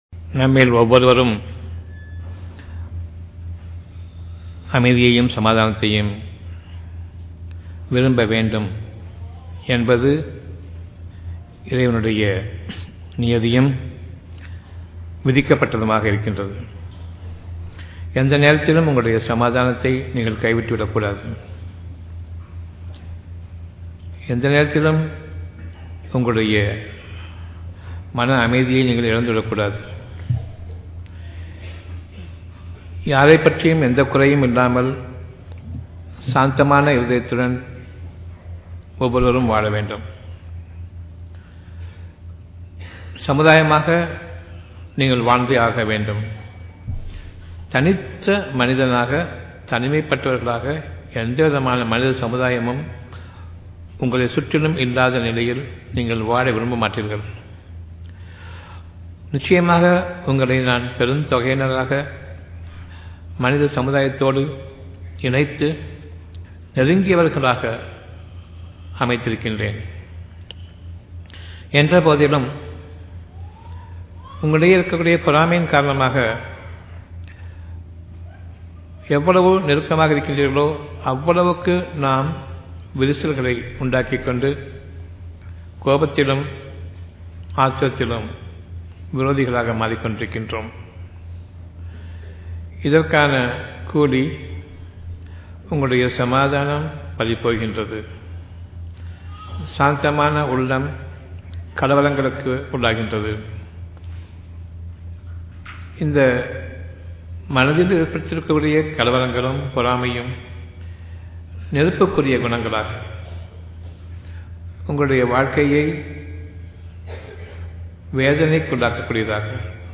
Sitra Auditorium